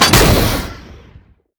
JackHammer_1p_02.wav